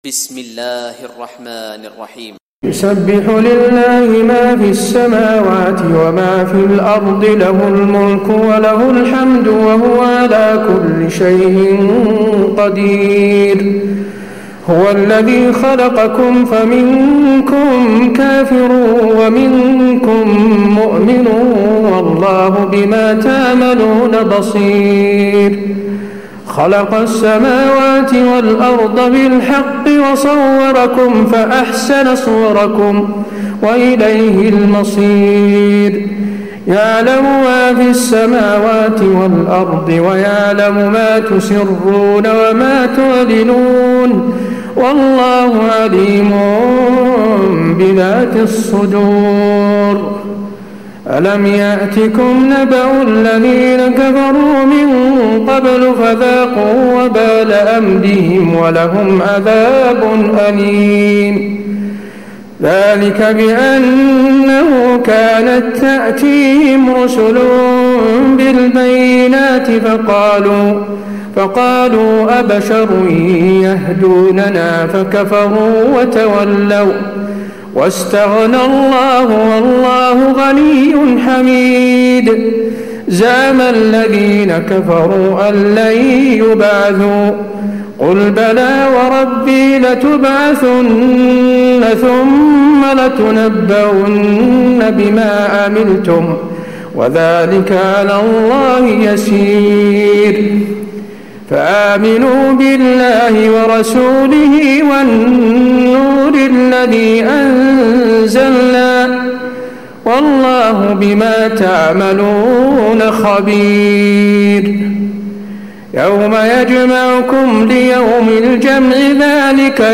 تراويح ليلة 27 رمضان 1436هـ من سورة التغابن الى التحريم Taraweeh 27 st night Ramadan 1436H from Surah At-Taghaabun to At-Tahrim > تراويح الحرم النبوي عام 1436 🕌 > التراويح - تلاوات الحرمين